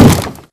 Sound / Minecraft / mob / zombie / wood3.ogg
wood3.ogg